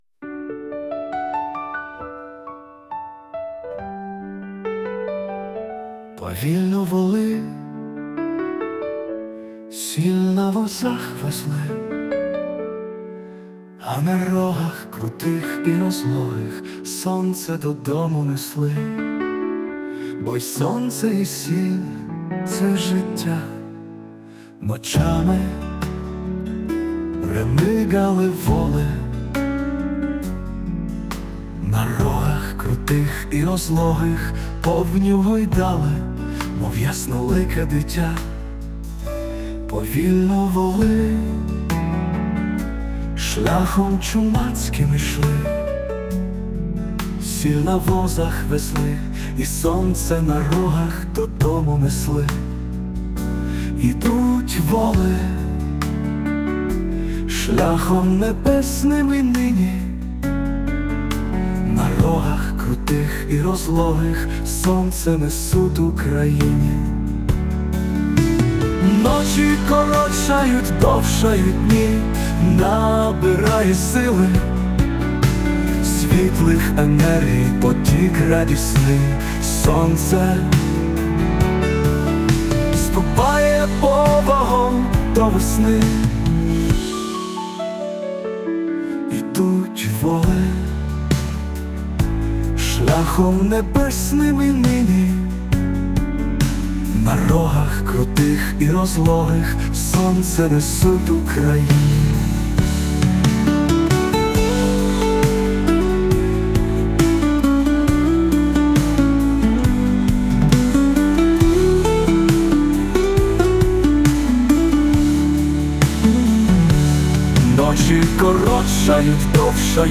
музику і виконання згенеровано ШІ
СТИЛЬОВІ ЖАНРИ: Ліричний